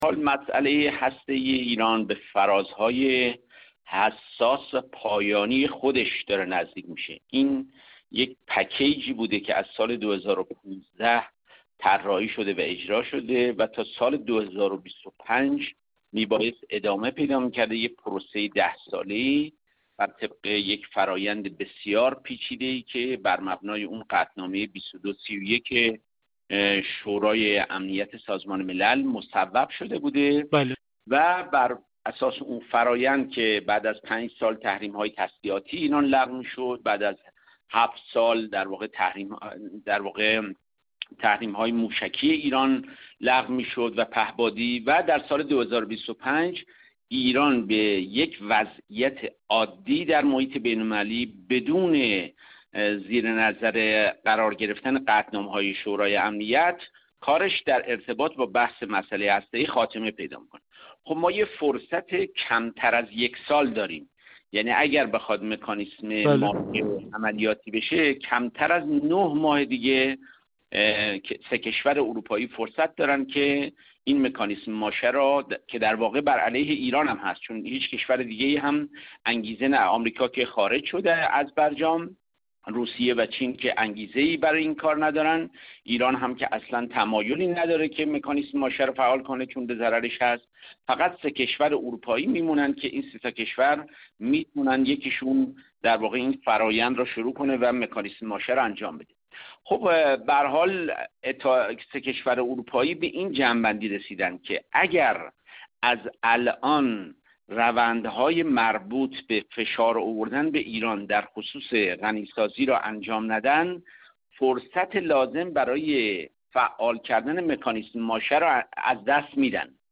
گفت‌‌وگو